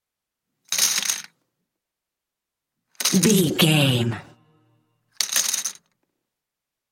Casino 10 chips table x3
Sound Effects
foley